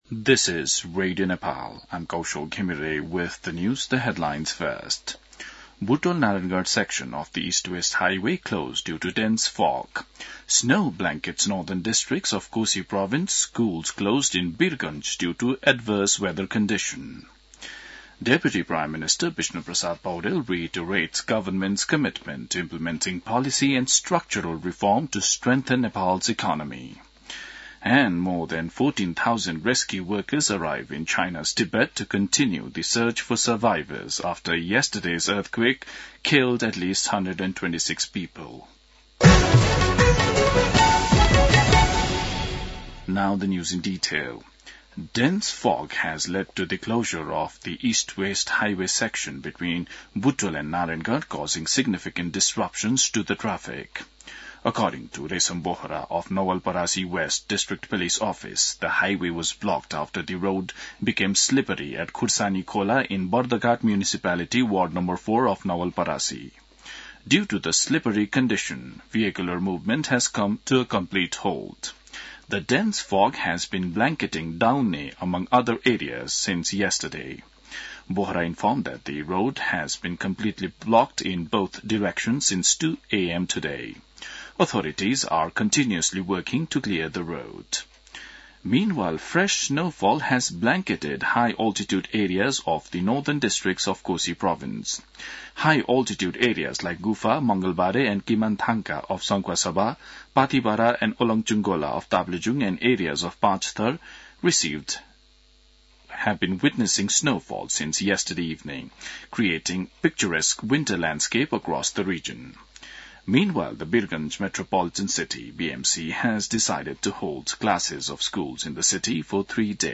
दिउँसो २ बजेको अङ्ग्रेजी समाचार : २५ पुष , २०८१
2-pm-news-1-2.mp3